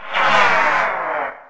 boogie_man_dies.ogg